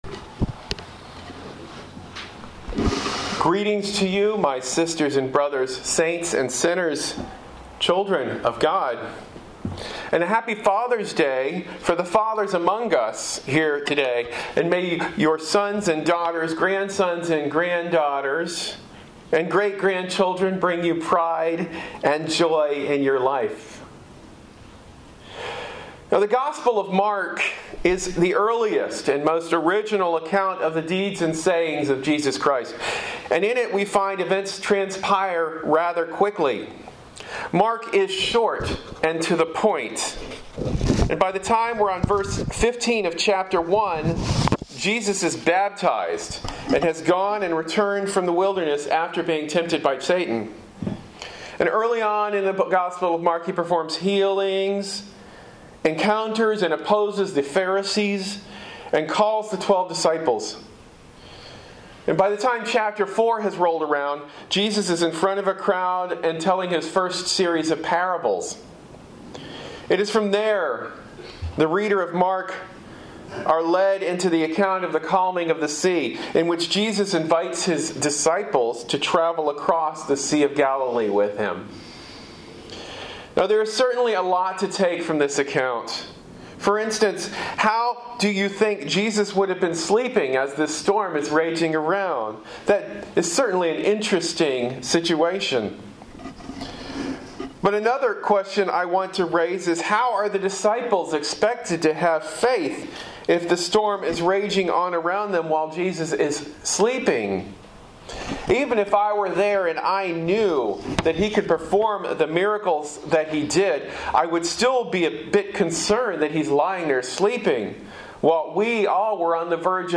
Sermon delivered at The Heritage of San Francisco.